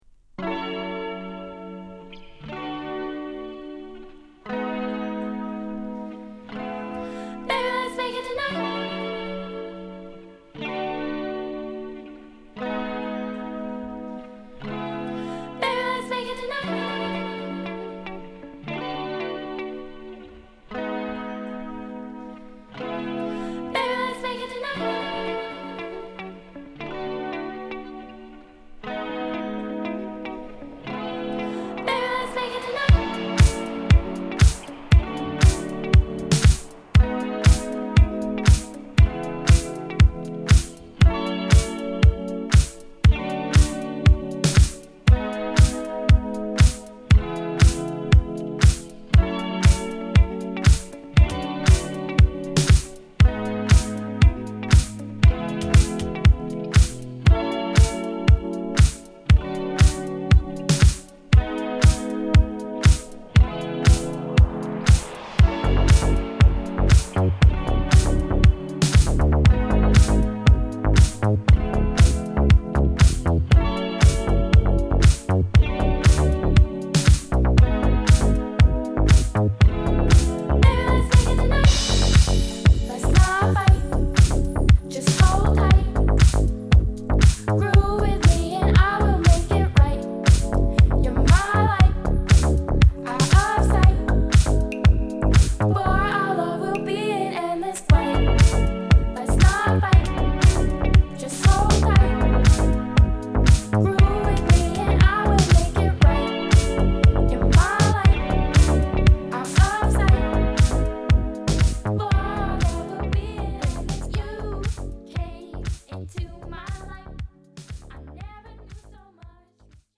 アルバム通して程良いポップ感を持ったナイスブギーを披露！
Boogie